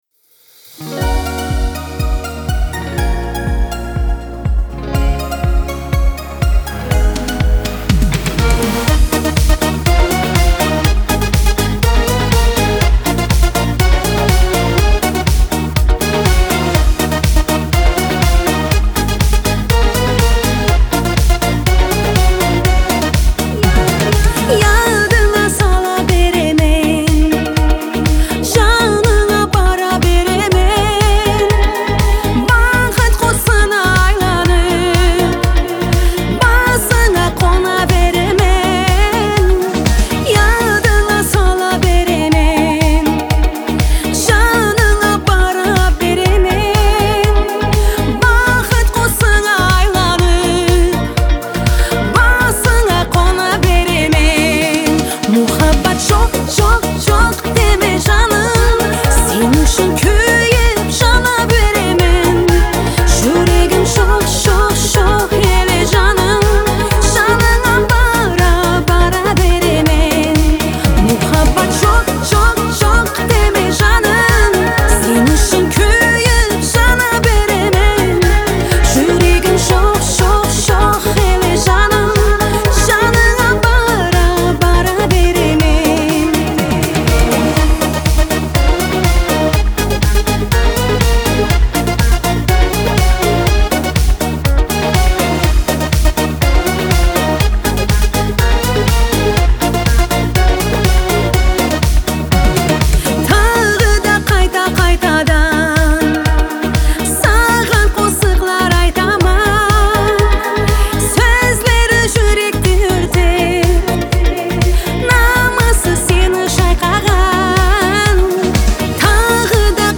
Трек размещён в разделе Узбекская музыка.